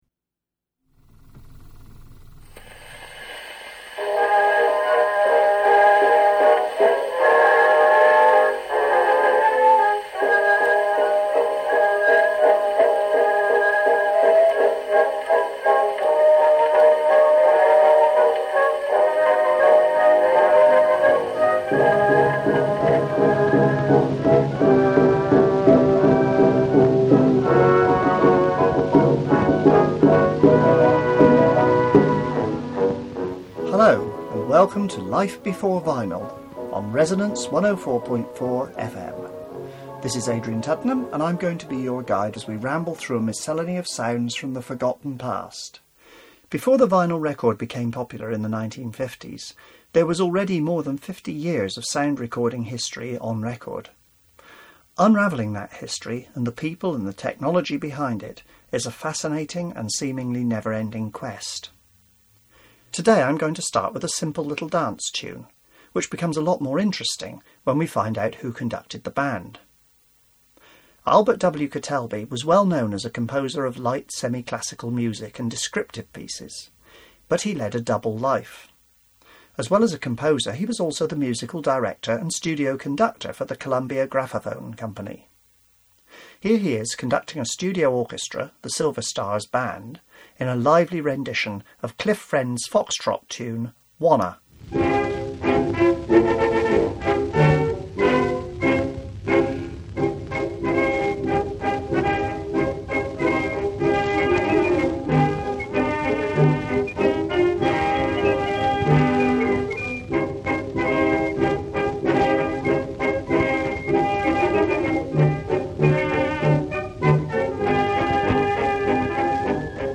The composer as a recording manager and conductor - A convoluted song from revue - A popular ballad as my grandmother would have heard it - The previous two song parodied, and lots more besides - A painting comes to life, theatrical effects in the 1920s.